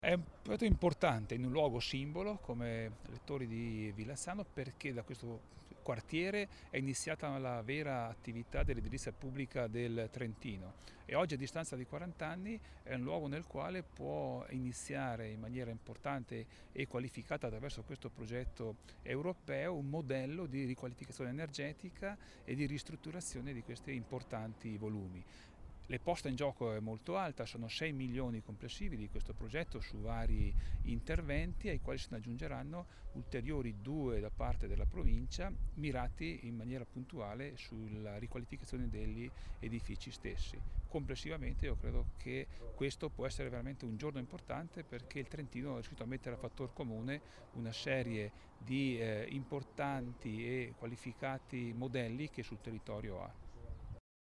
L'intervento presentato stamani nel corso di una conferenza stampa tenutasi sul tetto della Torre 6